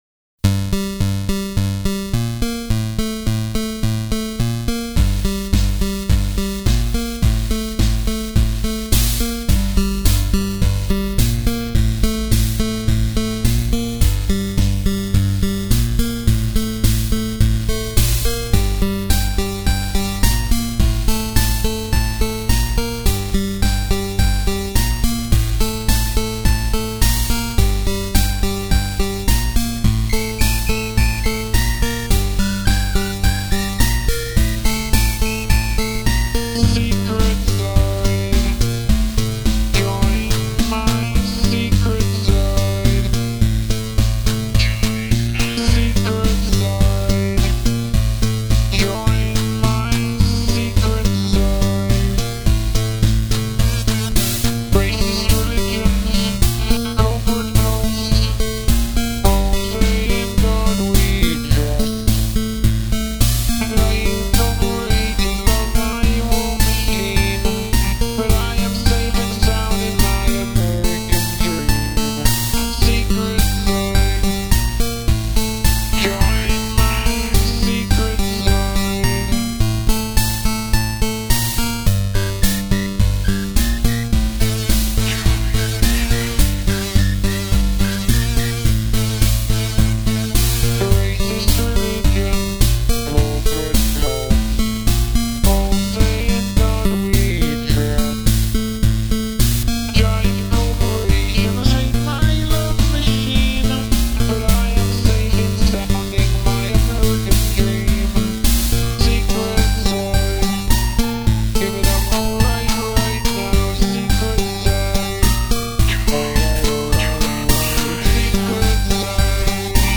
gameboy w nes & sid